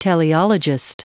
Prononciation du mot teleologist en anglais (fichier audio)
Prononciation du mot : teleologist